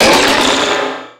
Cri de Méga-Mysdibule dans Pokémon X et Y.
Cri_0303_Méga_XY.ogg